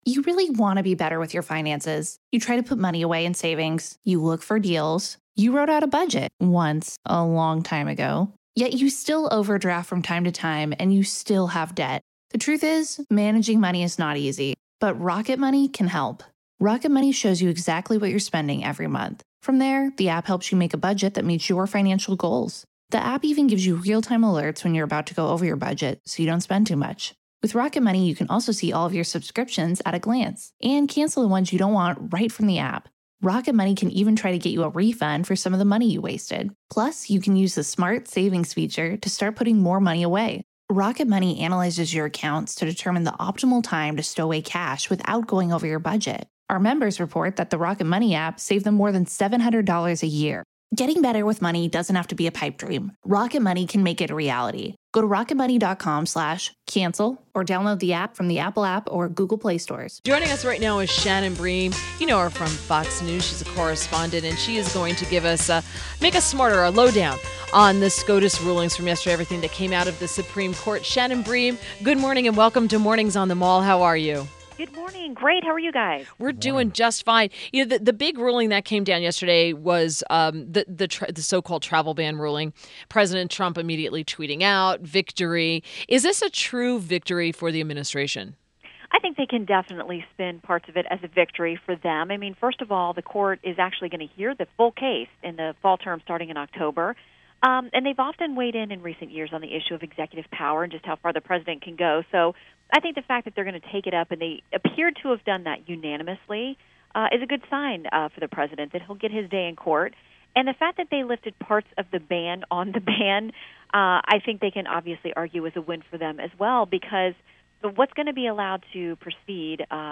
WMAL Interview - SHANNON BREAM 06.27.17
SHANNON BREAM - Fox News Correspondent Topic : SCOTUS rulings • The Supreme Court is taking on a new clash between gay rights and religion in a case about a wedding cake for a same-sex couple in Colorado.